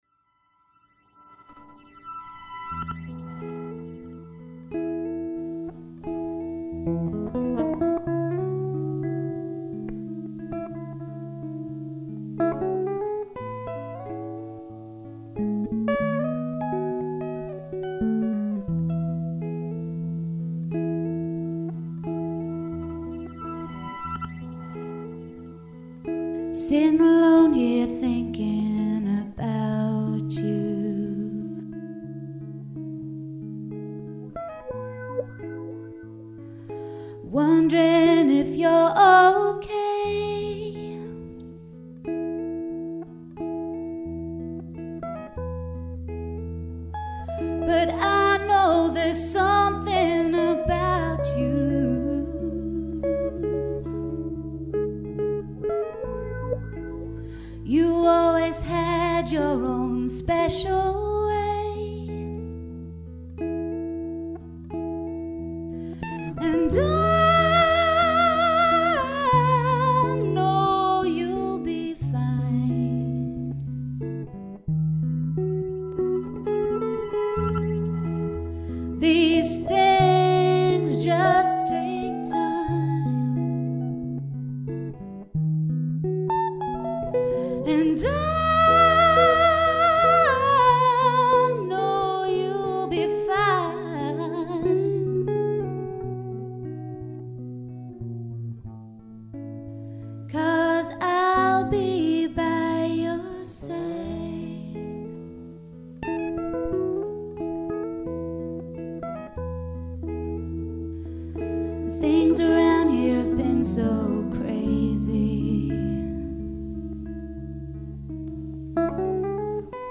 vocals
acoustic guitar, bass guitar, electric jazz guitar
electric guitar